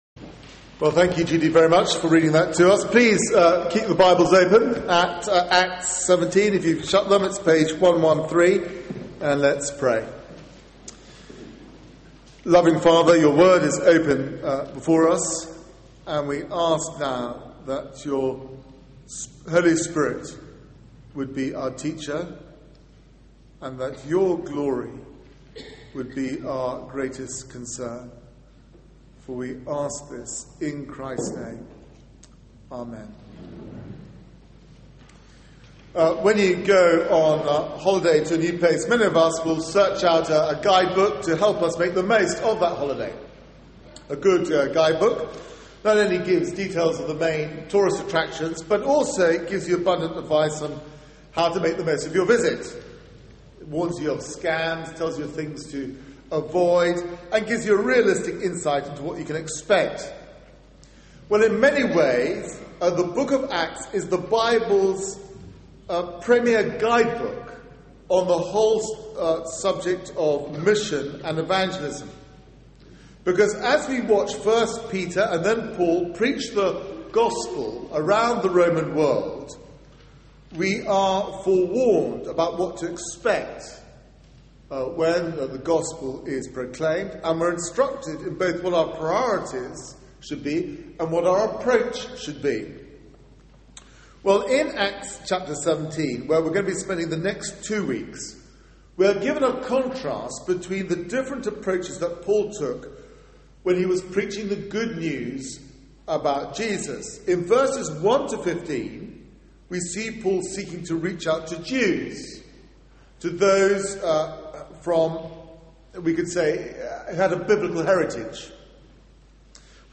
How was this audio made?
Media for 9:15am Service on Sun 07th Aug 2011 09:15 Speaker